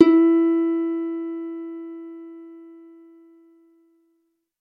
红酒杯手指演奏
描述：这是一个酒杯的声音，充满了水，玻璃用手指演奏。 使用Zoom H4n和Schertler Basik Pro记录样品。 没有后处理，除了裁剪和淡出。 由于玻璃杯产生的声音很小，所以噪音/声音的比例很低。
标签： 玻璃 C4 竖琴 捡起 红酒
声道单声道